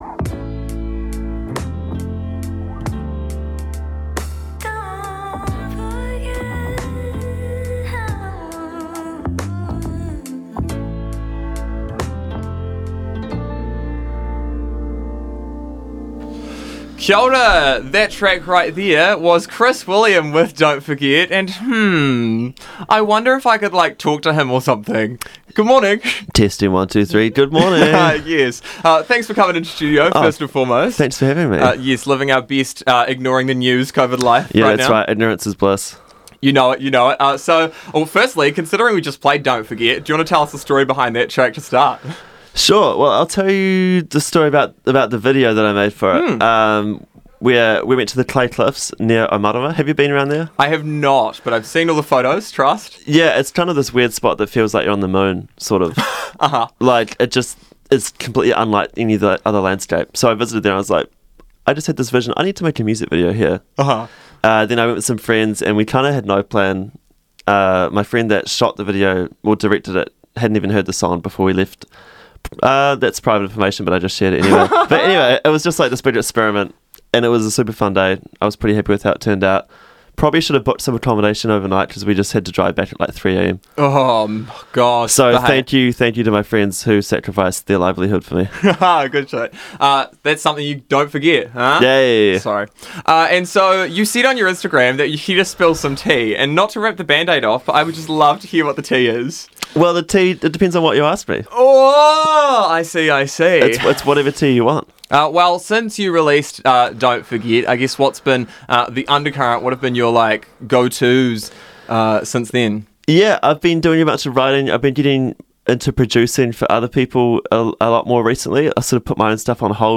Listen in here (and enjoy the tracks of his mid-interview as well):